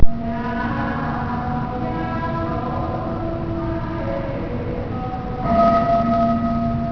Caodaists prostrate during a chant (hear it)
Caodaist temple, Tay Ninh, Vietnam